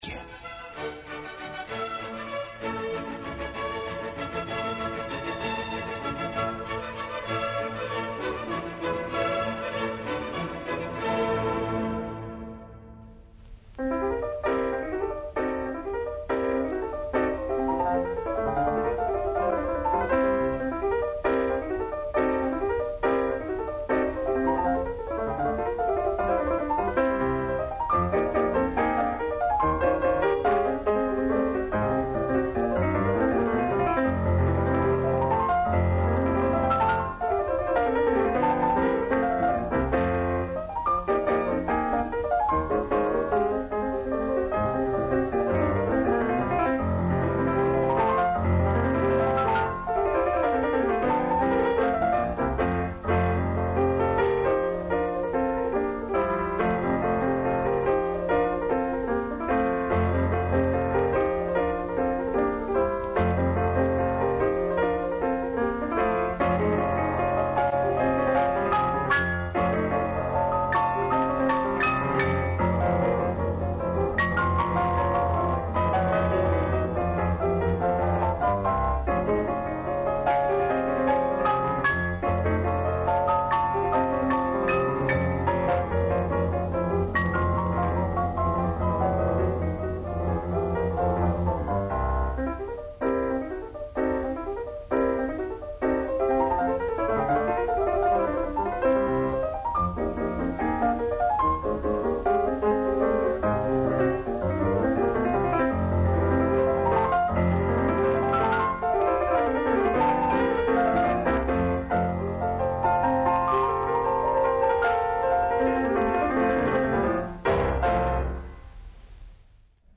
Поводом Дана Радио Београда, слушате раритететне архивке снимке из наше фонотеке